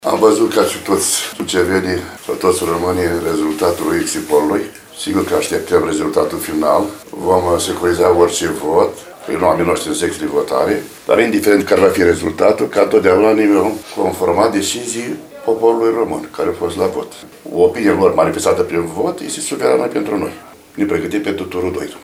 VIDEO / Declarații la ora 21 la sediile PNL și PSD Suceava, după primul tur al alegerilor prezidențiale
La sediul PSD Suceava, președintele IOAN STAN a fost mai rezervat în declarații.